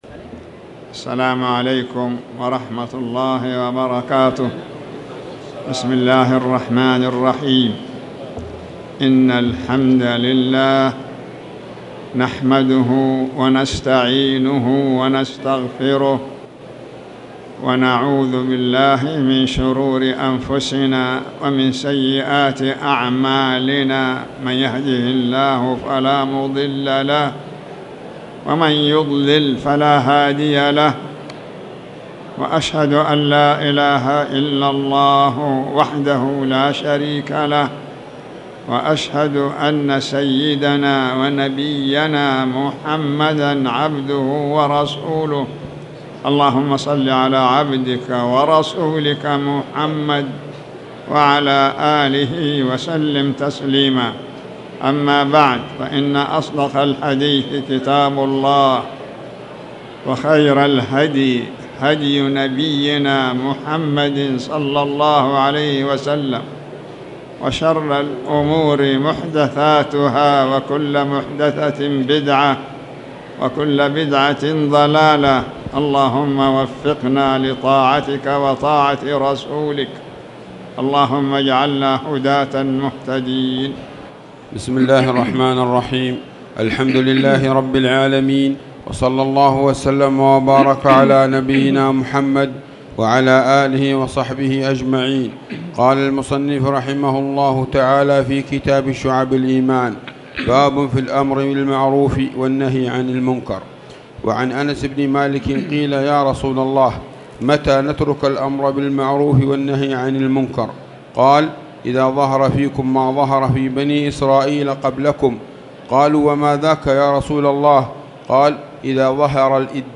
تاريخ النشر ١ جمادى الآخرة ١٤٣٨ هـ المكان: المسجد الحرام الشيخ